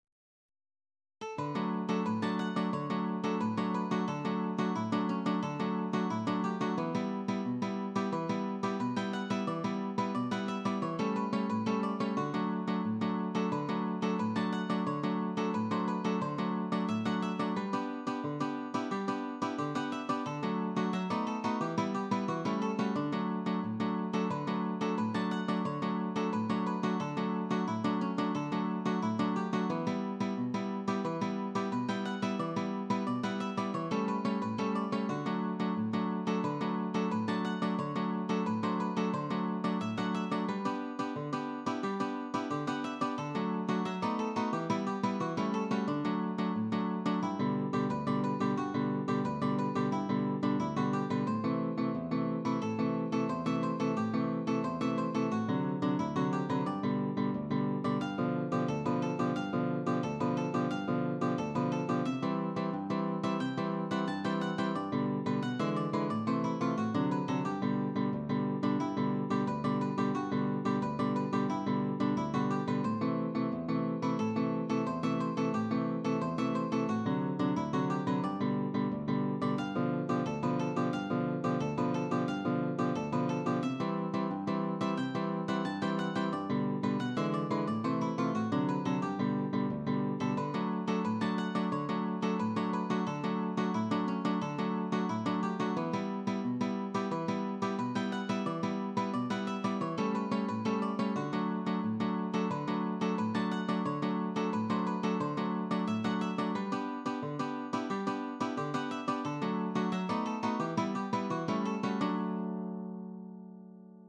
(choro for 2 guitars)